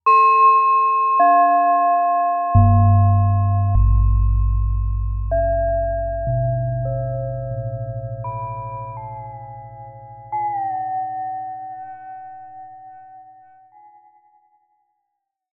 No other effects processing was used other than some normalization, and each example is a single track. All effects and pitch modulation were improvised using the FLUX bows.